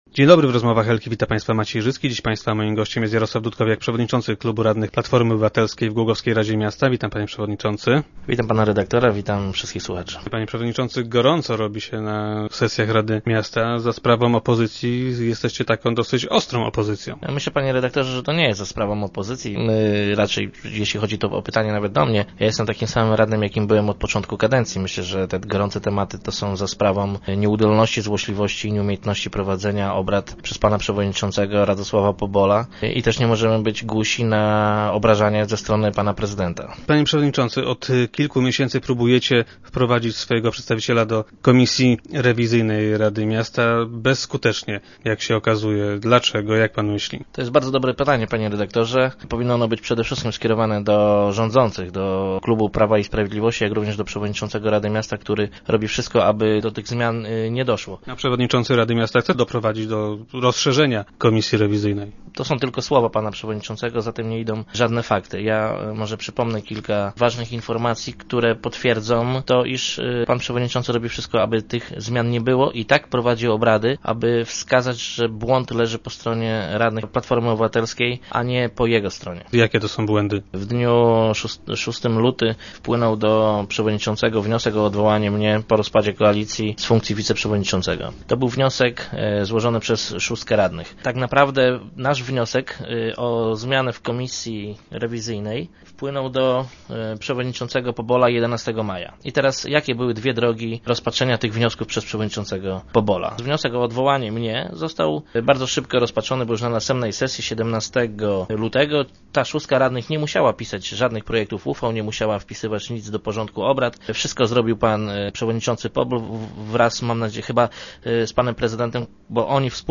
Jak powiedział Jarosław Dudkowiak, przewodniczący klubu PO i dzisiejszy gość Rozmów Elki, wynika to z niechęci przewodniczącego rady miasta do załatwienia tej sprawy.